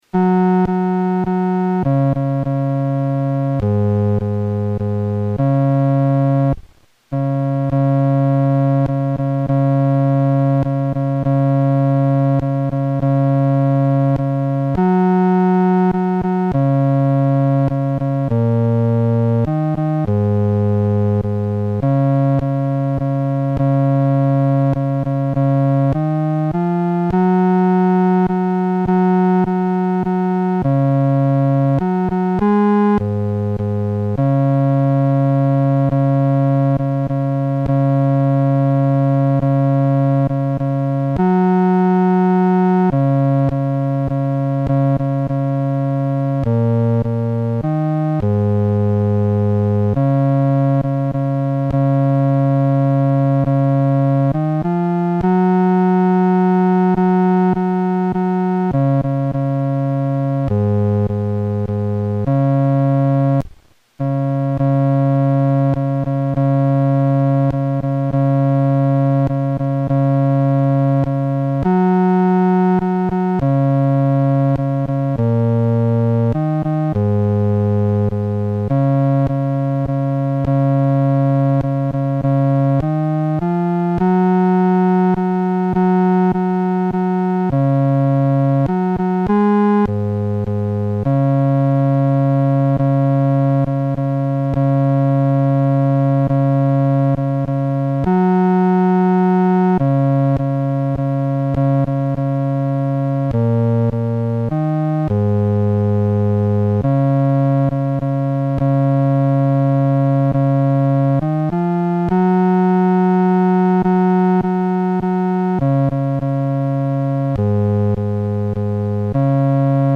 伴奏
男低